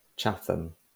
Anheddiad dynol ym Mwrdeistref Sirol Caerffili yw Chatham ("Cymorth – Sain" ynganiad ); (Saesneg: Chatham).[1] Mae'n rhan o sir hanesyddol Sir Forgannwg ac yn eistedd o fewn cymuned Bedwas.